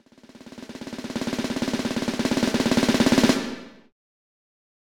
Countdown music